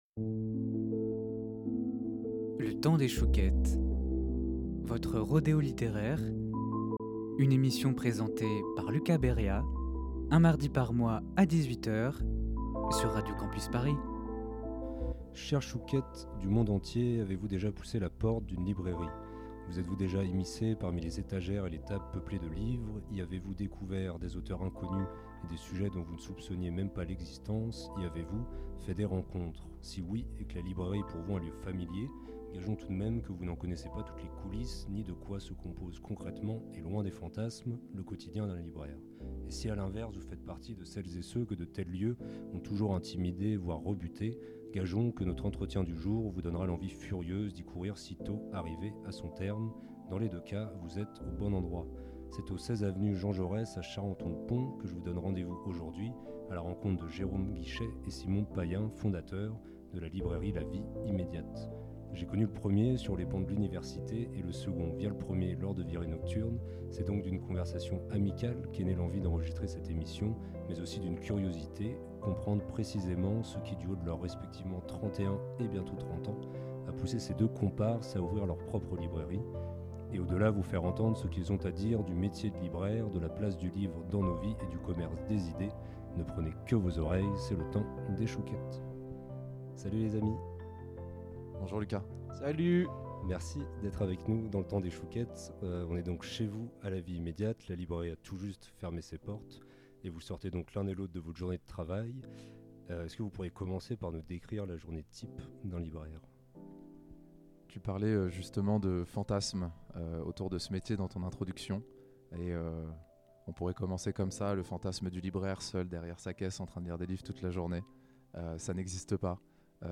La Vie Immédiate Partager Type Entretien Culture mardi 4 novembre 2025 Lire Pause Télécharger Chères chouquettes du monde entier